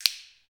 Index of /90_sSampleCDs/Roland - Rhythm Section/PRC_Clap & Snap/PRC_Snaps